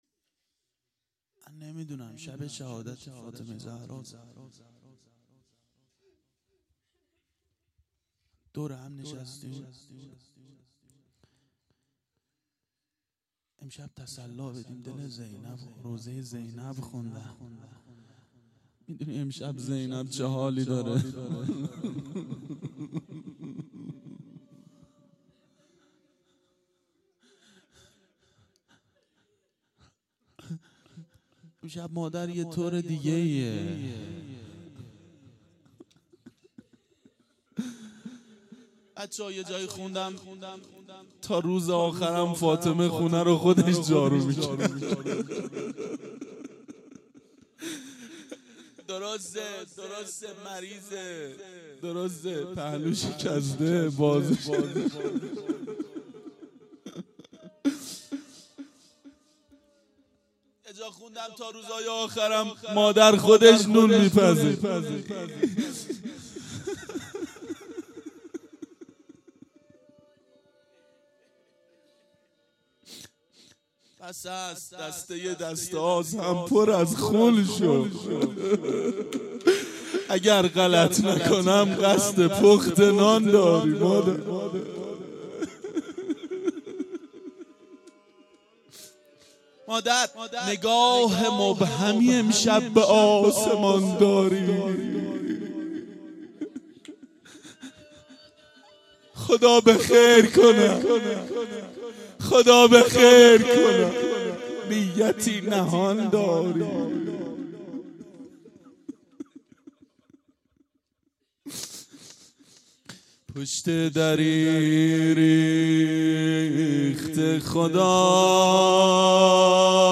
روضه حضرت زهرا سلام الله علیها
فاطمیه اول 92 عاشقان اباالفضل علیه السلام منارجنبان
00-روضه-حضرت-زهرا.mp3